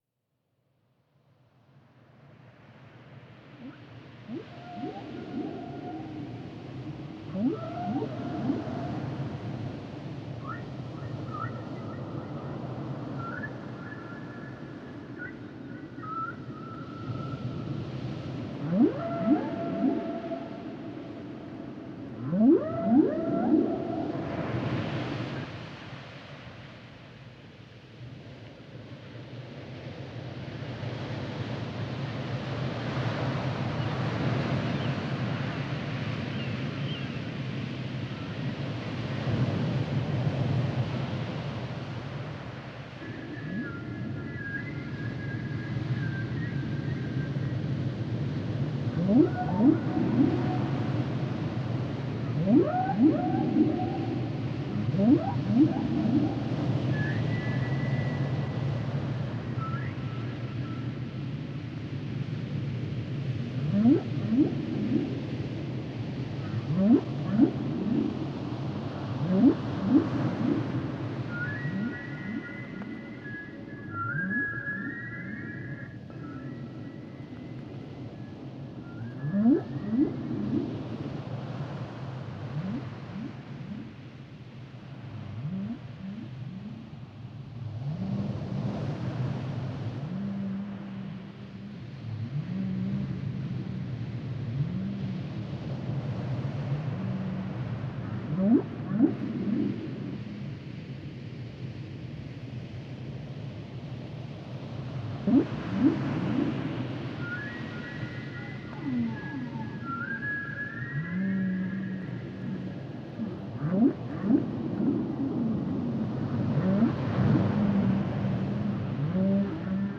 Sounds and Songs of theHumpback Whale